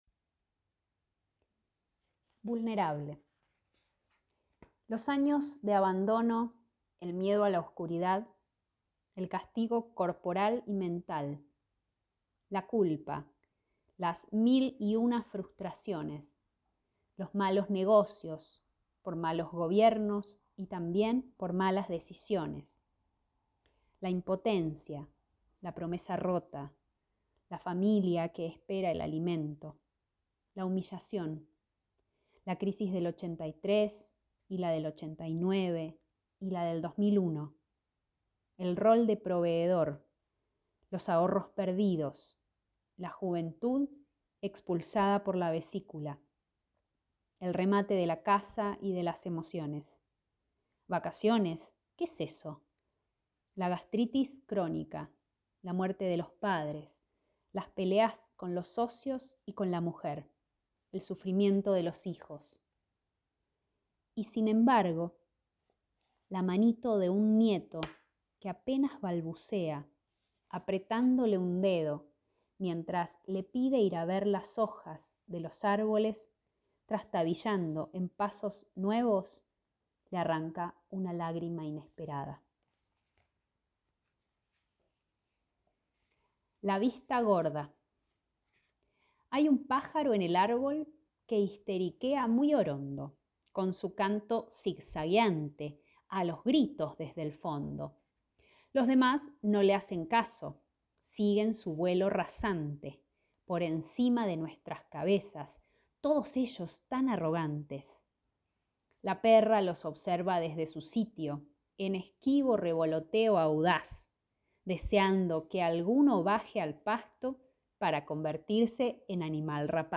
Tres Poemas, en la voz de su autora